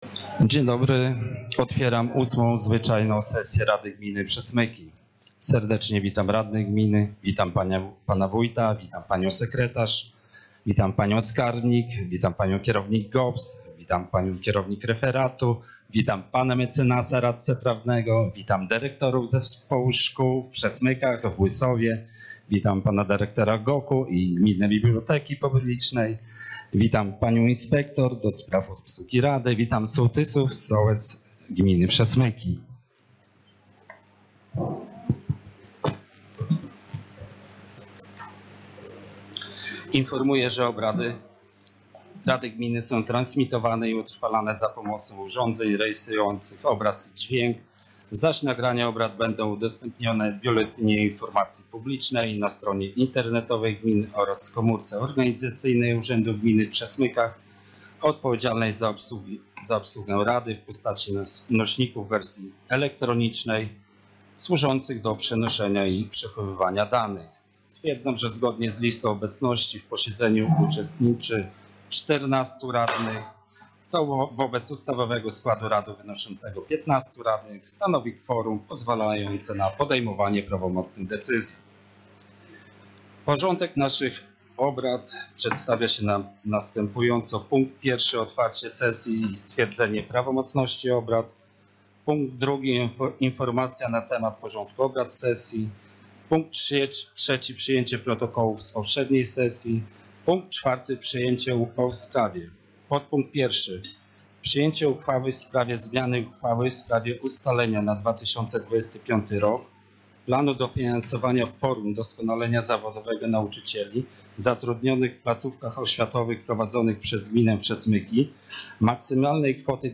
Sesja Rady Gminy Przesmyki – 18.02.2025